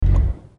engineoff.mp3